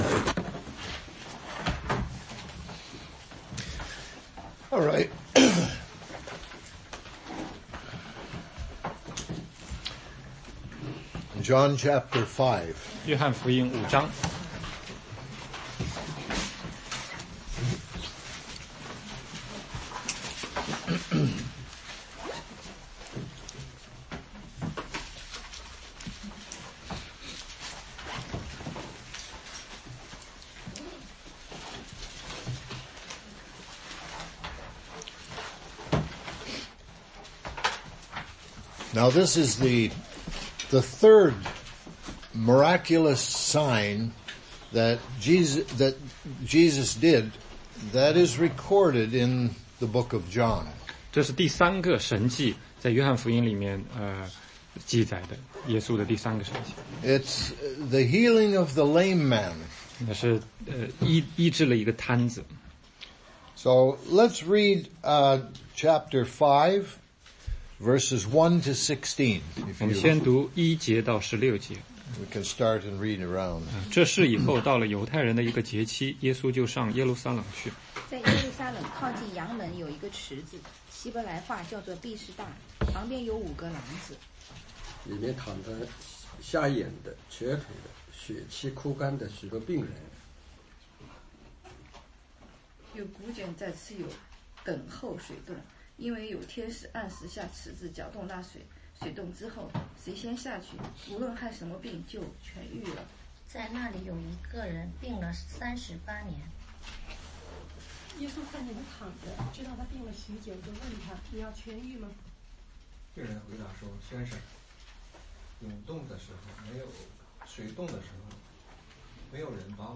16街讲道录音 - 约翰福音5章1-16